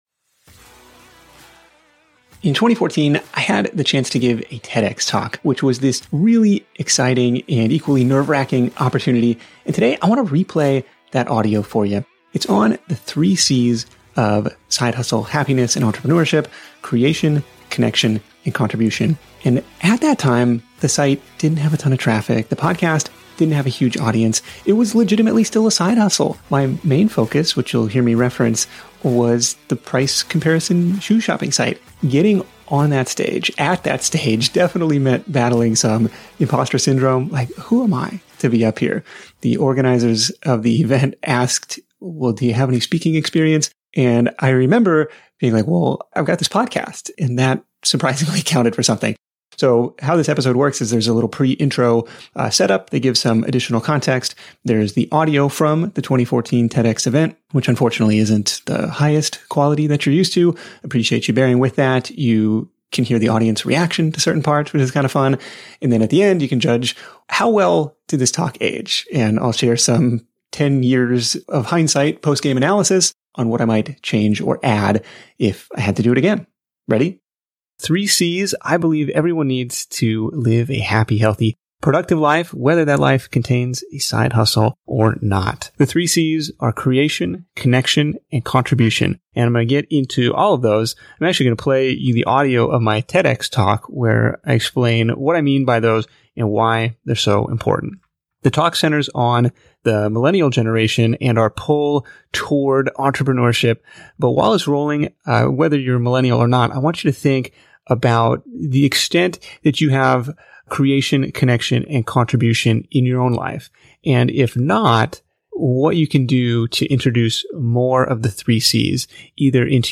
In this episode I share the audio from my TEDx talk, which breaks down the 3 C's and why they're so important.